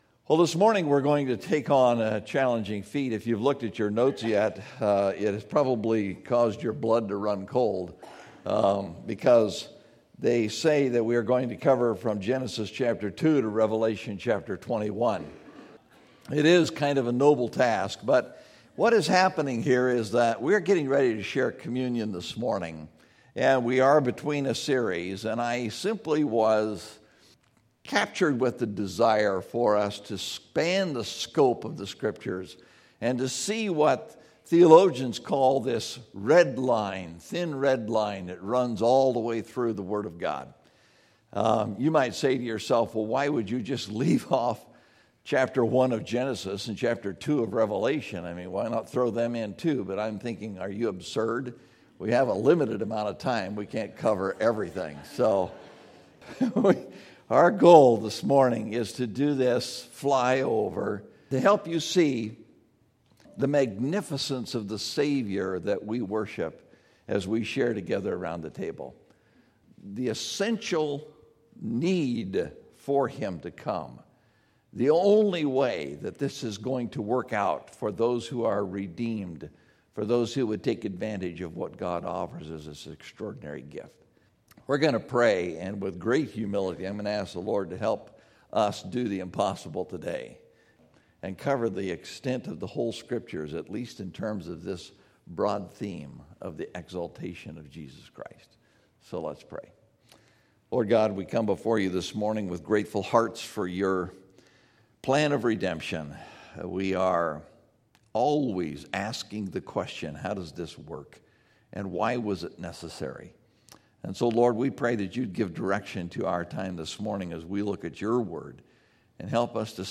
The Thin Red Line (Genesis 2:25 – Revelation 21:4) – Mountain View Baptist Church